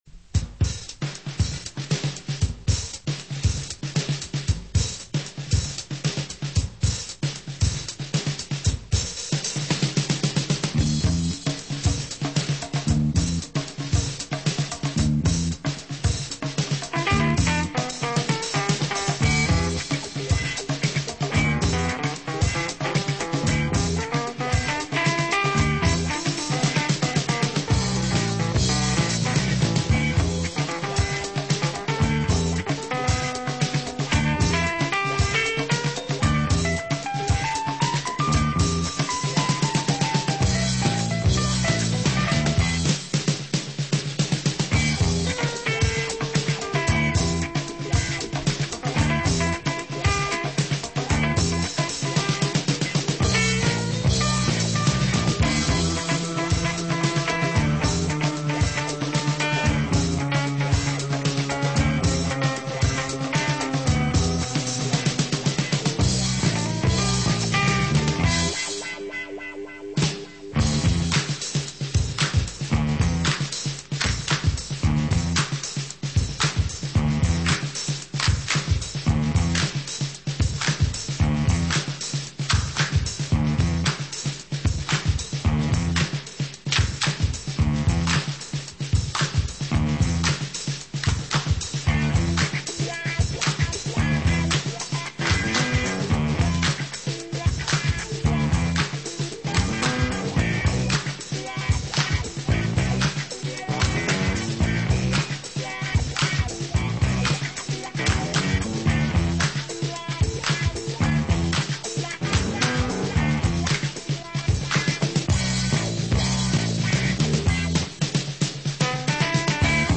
und ein paar funky-New-Breakz!